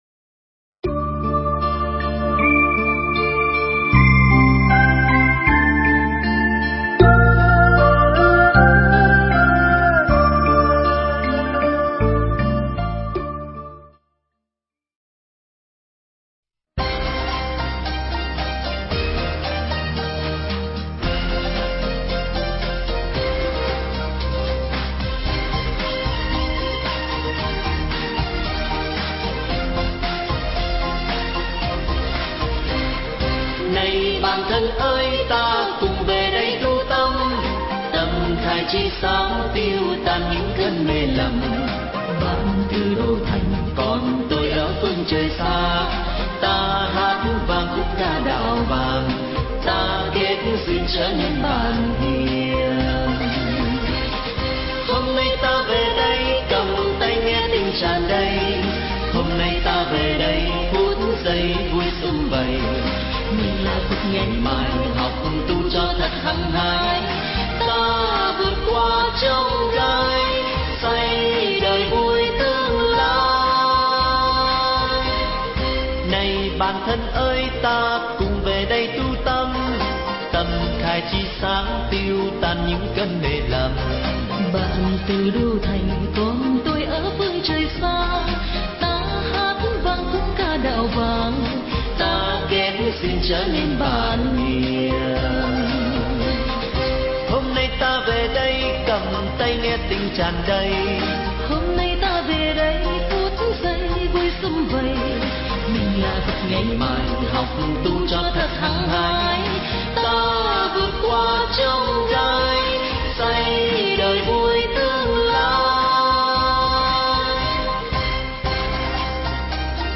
thuyết Pháp
giảng tại khoá tu mùa hè